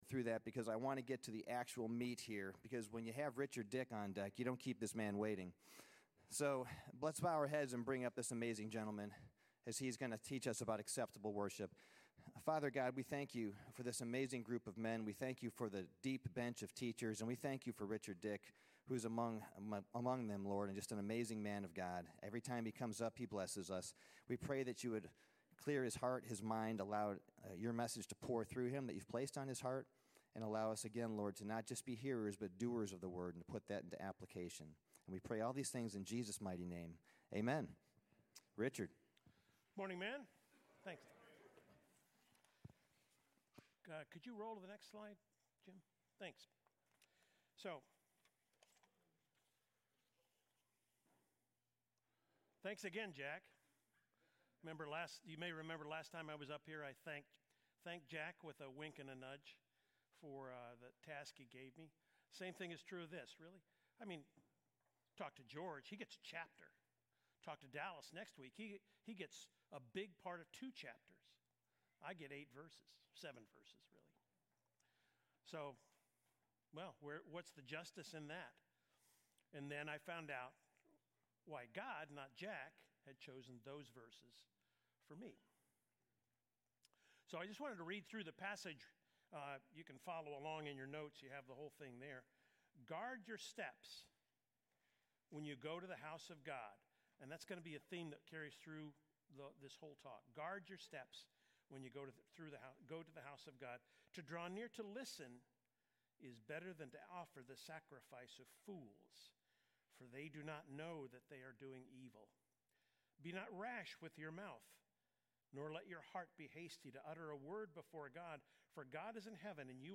Basecamp Bible Study